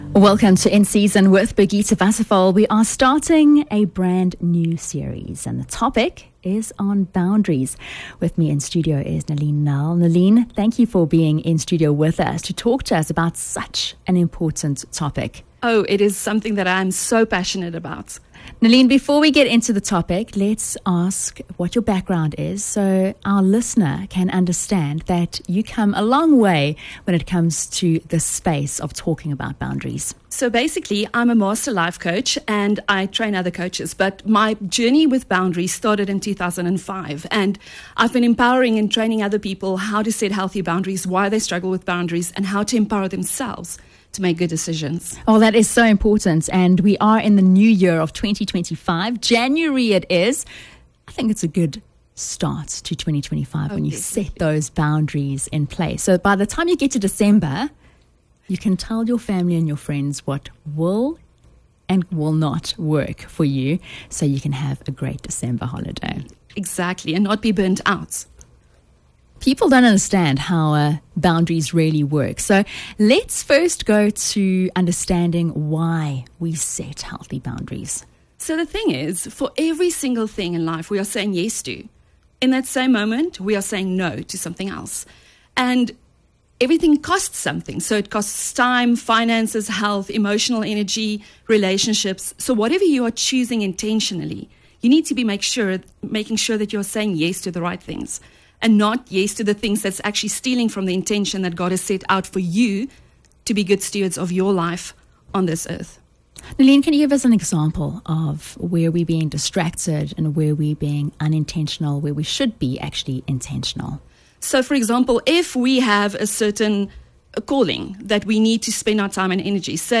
Tune in for an insightful conversation on protecting your peace and fostering healthier relationships.